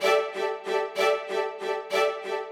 Index of /musicradar/gangster-sting-samples/95bpm Loops
GS_Viols_95-A1.wav